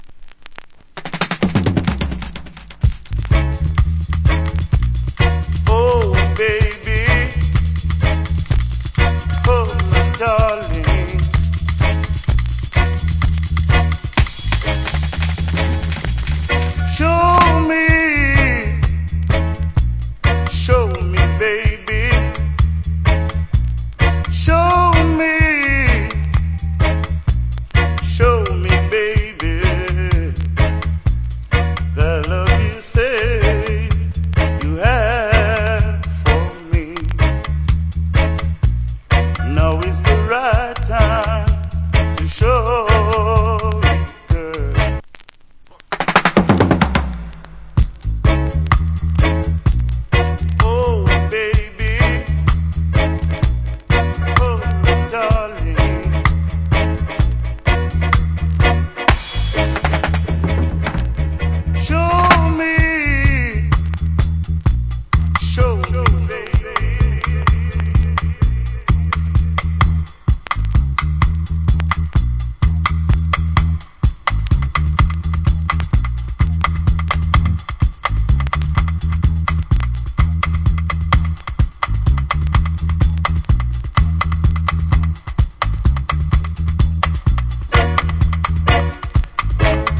Nice Vocal.